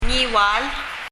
「Ngi」の部分で、「Ng」をはっきり発音するパラオ人だと、日本人の耳には「ニ」と「ギ」の中間のような音に聴こえることがあるのかもしれません。 そして最初の「N」の鼻濁音の音が、日本語カナ表記では「オ」となってしまったのかもしれません。
Ngiwal [ŋiwa:l] 日本語で発行されている、パラオの観光パンフレットのなかに、このニワール州のカナ表記として 「オギワル州」 と書いてあります。